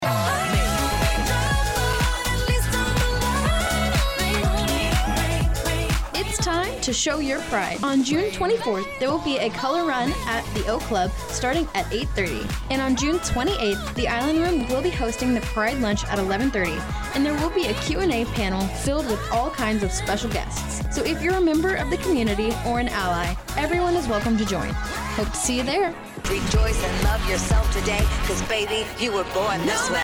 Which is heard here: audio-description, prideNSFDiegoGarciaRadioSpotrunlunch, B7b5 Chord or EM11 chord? prideNSFDiegoGarciaRadioSpotrunlunch